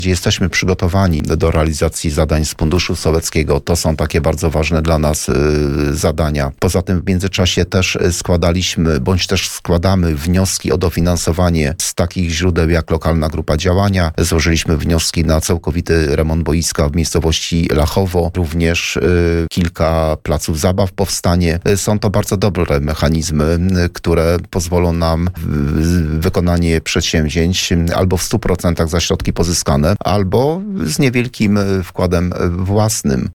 Jak mówił na naszej antenie wójt Józef Wiśniewski, gmina szuka możliwości dofinansowania swoich projektów.